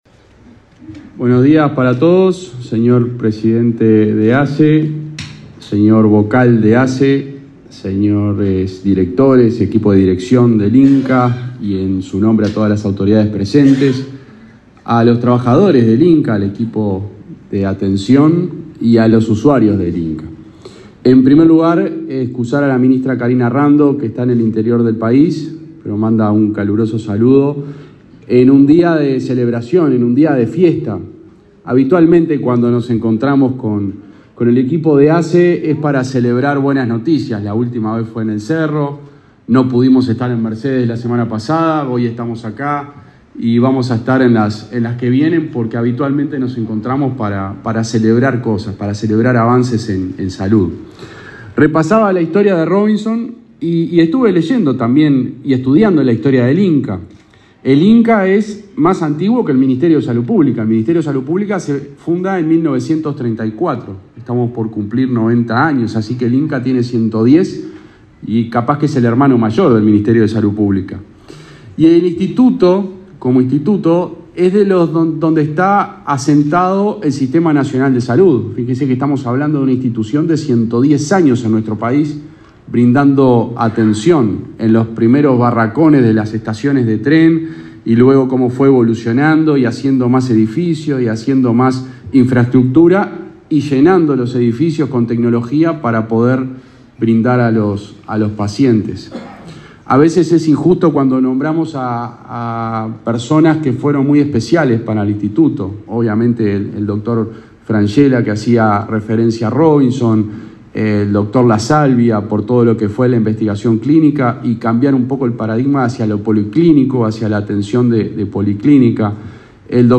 Palabra de autoridades en aniversario del Instituto Nacional del Cáncer
Palabra de autoridades en aniversario del Instituto Nacional del Cáncer 23/11/2023 Compartir Facebook X Copiar enlace WhatsApp LinkedIn Este jueves 23 en Montevideo el subsecretario de Salud Pública, José Luis Satjian, y el presidente de la Administración de los Servicios de Salud del Estado (ASSE), Leonardo Cipriani, participaron del acto por el 110.° aniversario del Instituto Nacional del Cáncer.